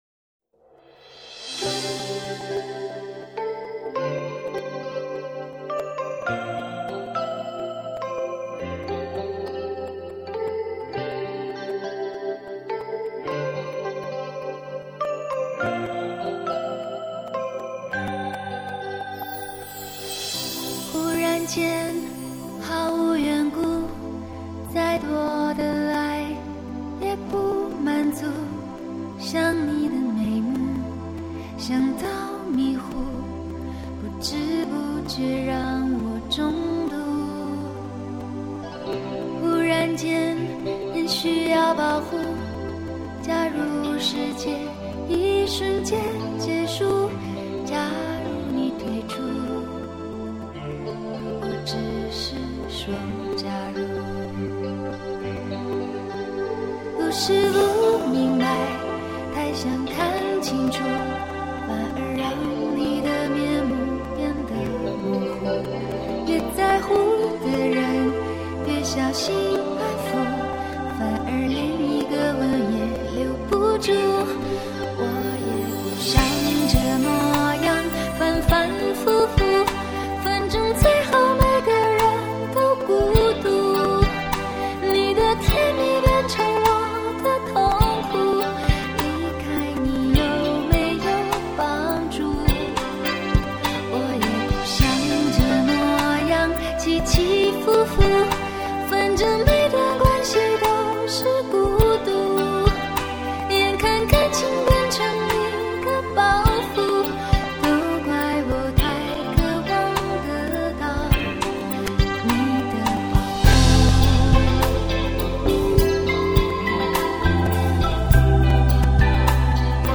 chinese song